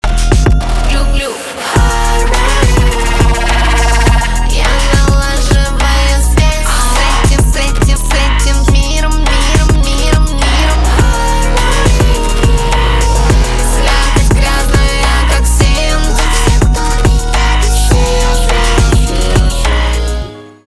электроника
битовые , басы , качающие , громкие